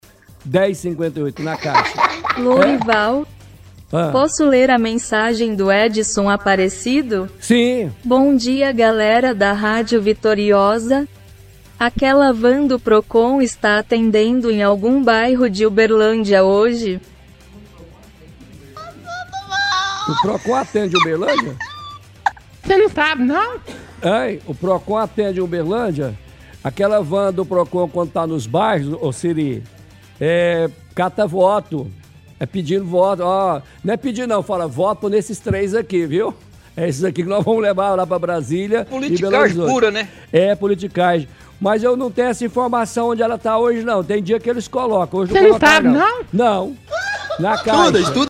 – Ouvinte questiona se o Procon Móvel está atendendo em algum bairro.